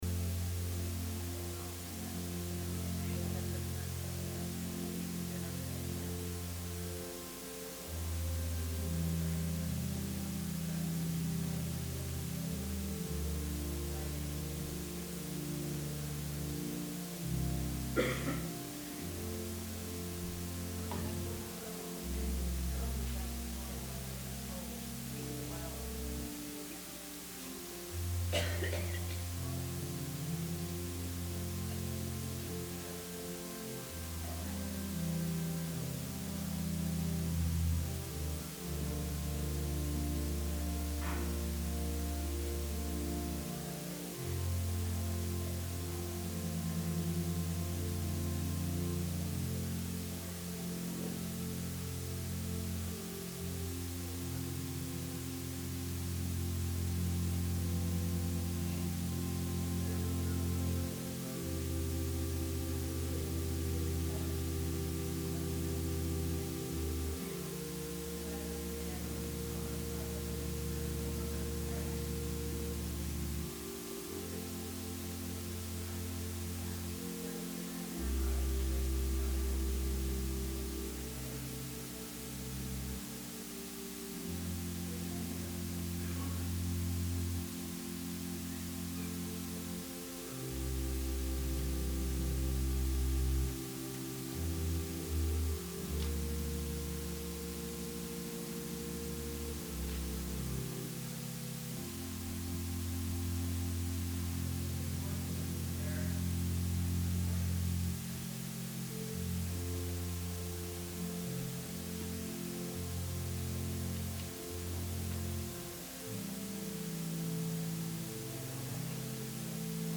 Sermon – February 23, 2020 – Advent Episcopal Church